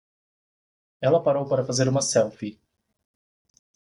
Pronounced as (IPA)
/paˈɾo(w)/